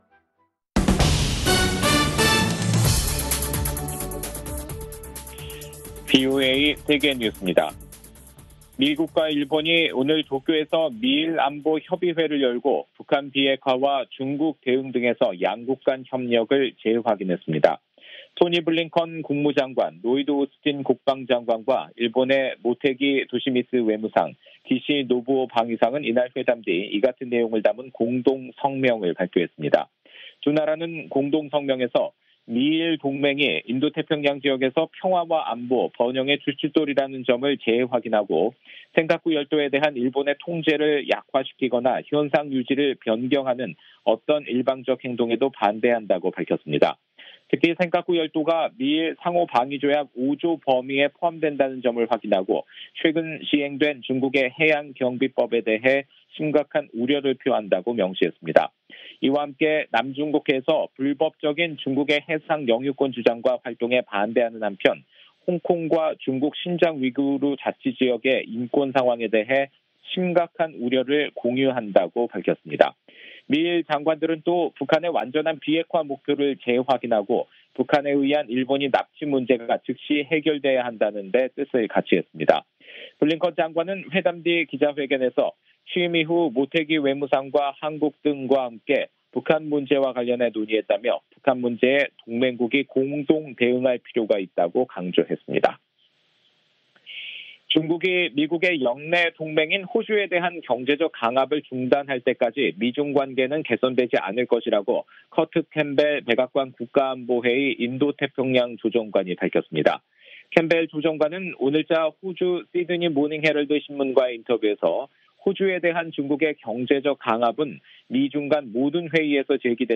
VOA 한국어 간판 뉴스 프로그램 '뉴스 투데이', 2021년 3월 16일 2부 방송입니다. 미국과 일본의 외교와 국방 장관들이 회담을 갖고, 미-일 동맹은 물론 한국을 포함한 3자 협력을 통해 북한과 중국 문제등에 대처하기로 의견을 모았습니다. 북한의 김여정 노동당 부부장이 미-한 연합군사훈련을 비난하는 담화를 발표했습니다.